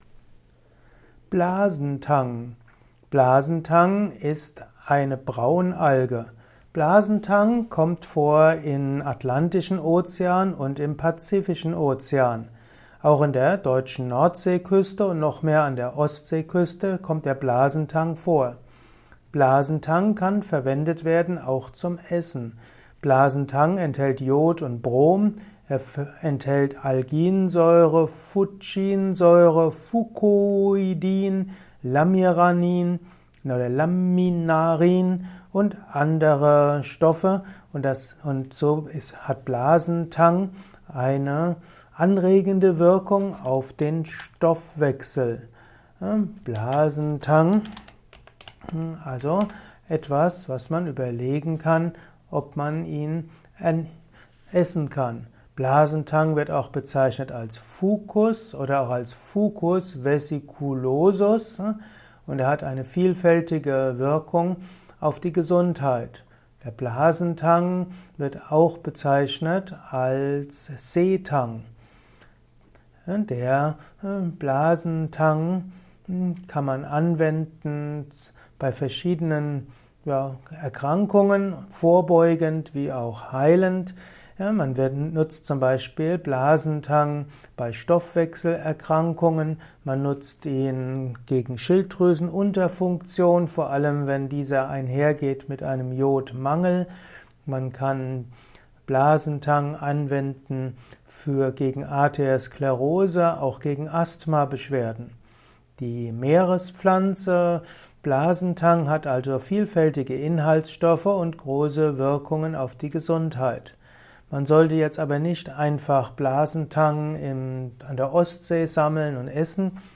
Kompakte Informationen zu Blasentang in diesem Kurzvortrag